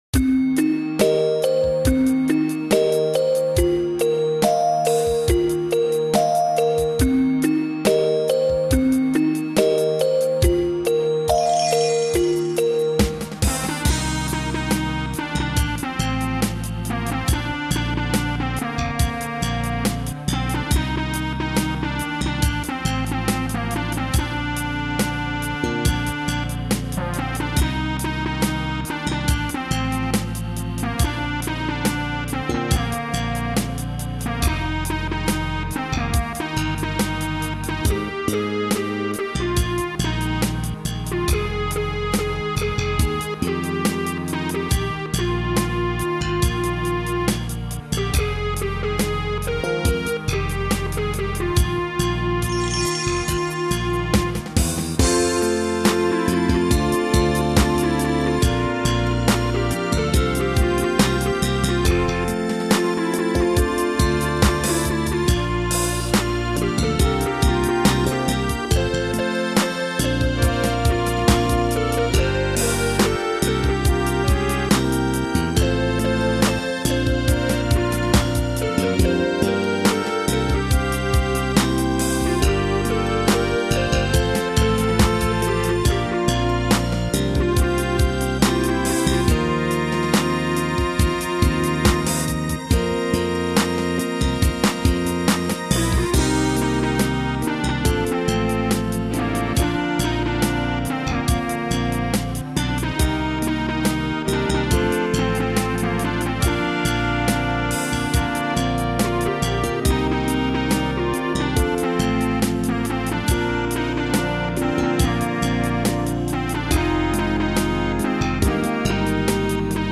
pop song